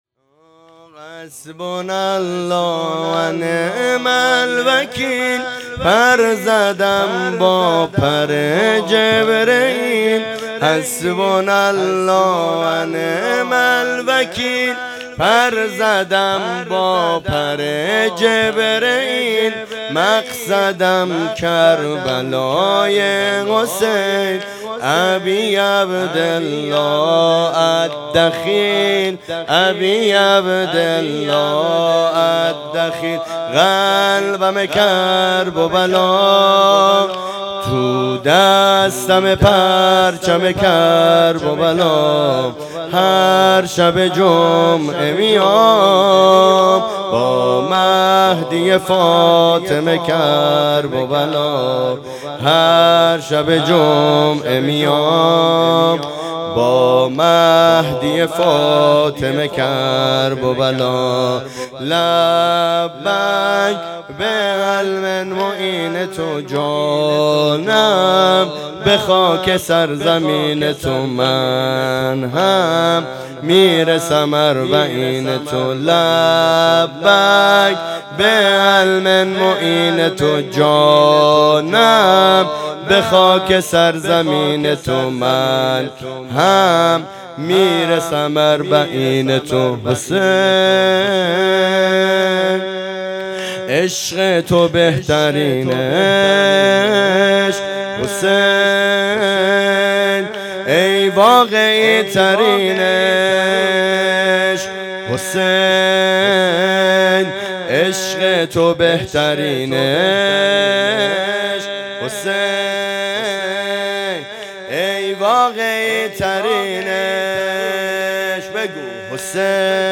شب اول محرم -5-4-1404
حسبنا الله و نعم الوکیل زمینه شب اول محرم